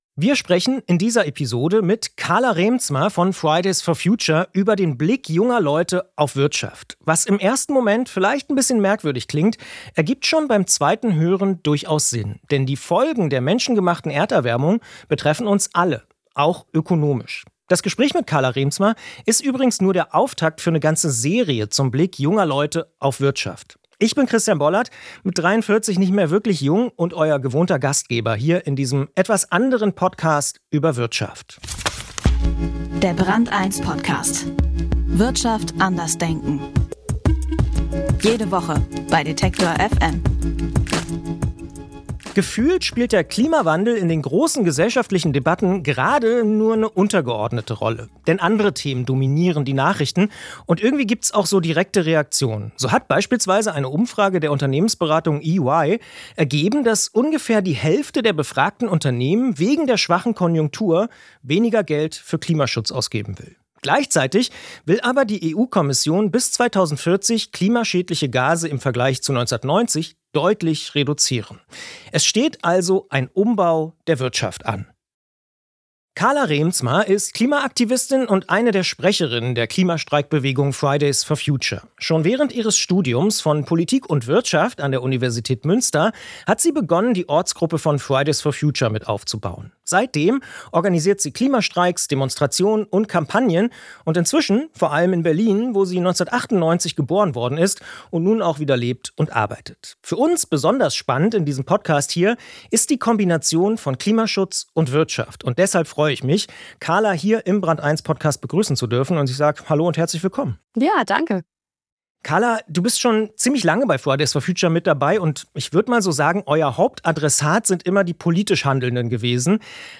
Carla Reemtsma von Fridays for Future im Gespräch über die wechselseitige Beziehung von Klima und Wirtschaft.